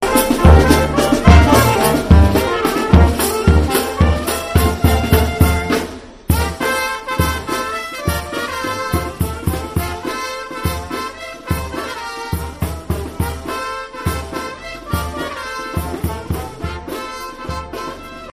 Las charangas se despiden de San Mateo 2022